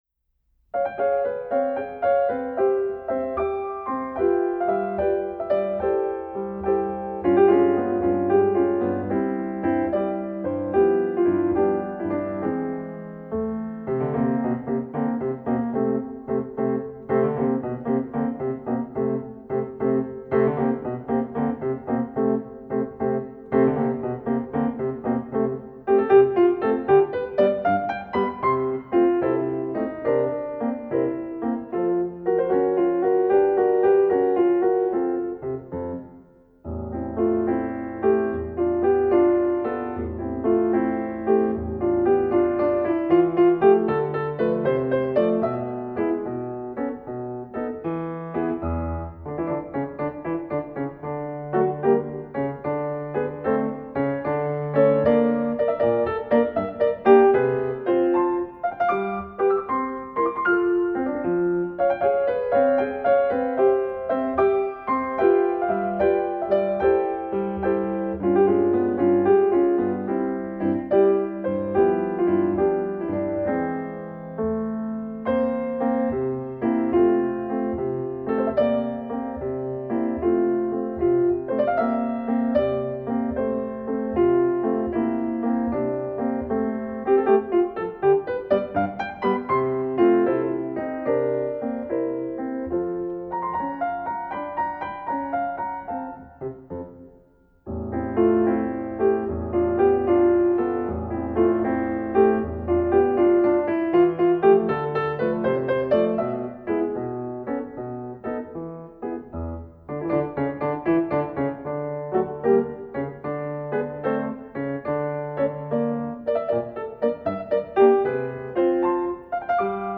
Voicing: High Voice Collection